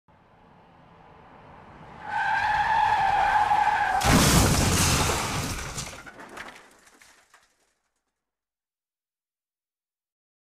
دانلود صدای ترمز ماشین 3 از ساعد نیوز با لینک مستقیم و کیفیت بالا
جلوه های صوتی